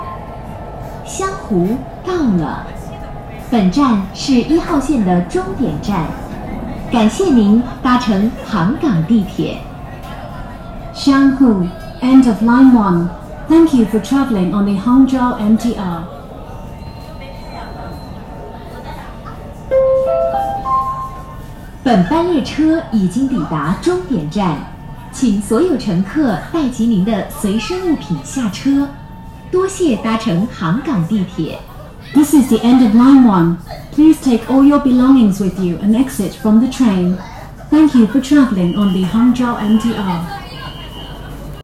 杭州地铁一号线湘湖站到站和终点站清客广播.ogg